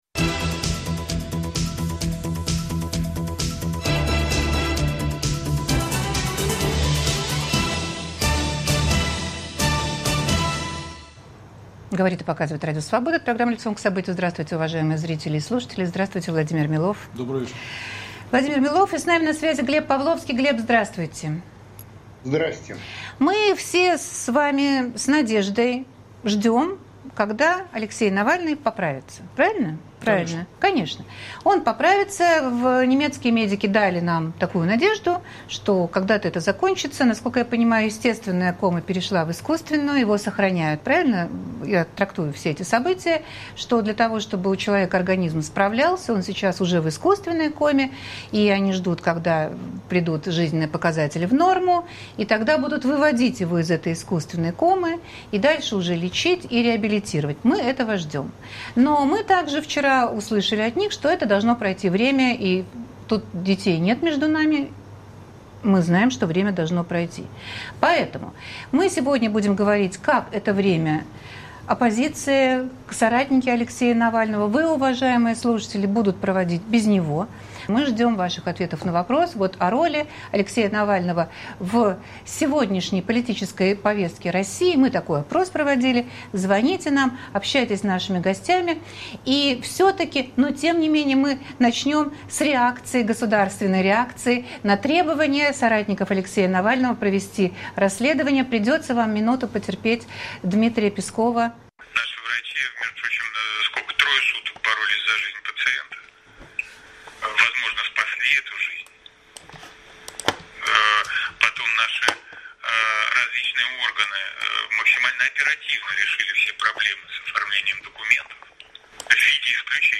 Сумеют ли коллеги Навального компенсировать его временное отсутствие в российской политической жизни? В гостях политик Владимир Милов и политолог Глеб Павловский.